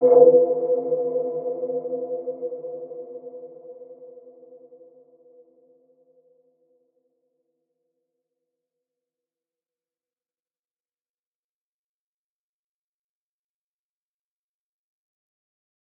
Dark-Soft-Impact-B4-p.wav